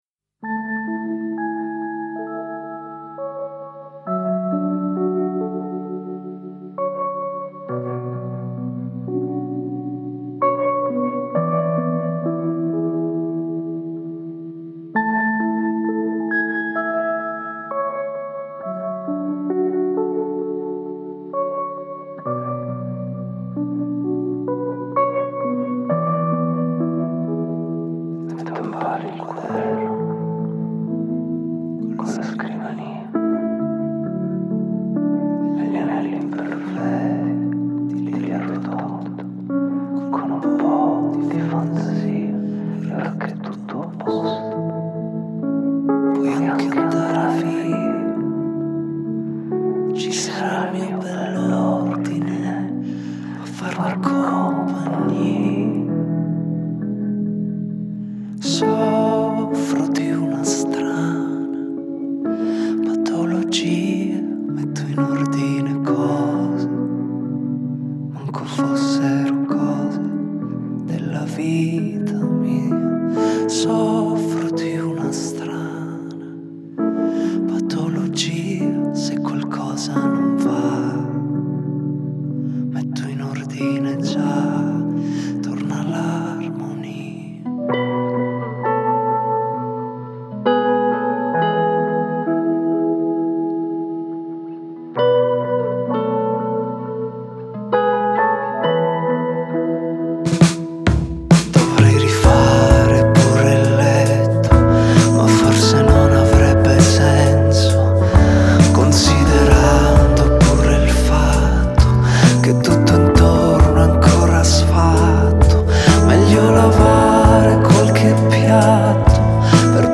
Genre: Pop Rock, Indie, Alternative